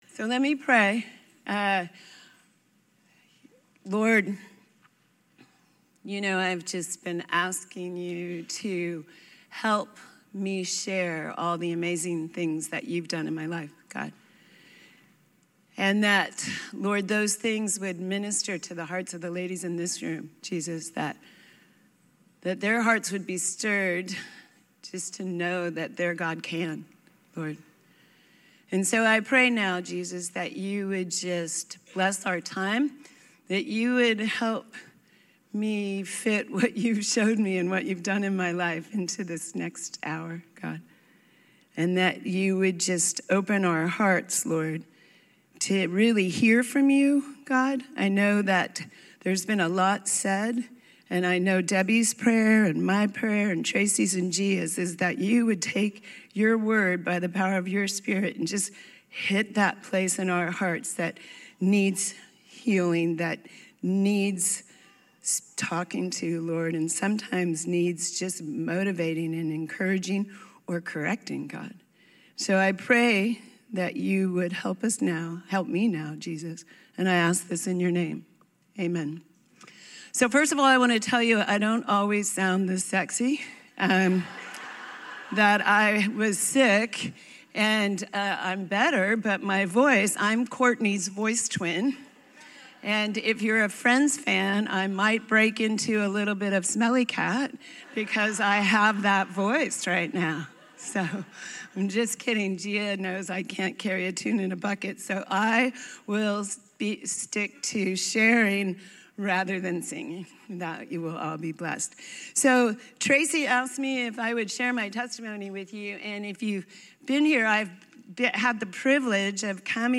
Testimony - 2022 Resolve Women's Stand Firm Conference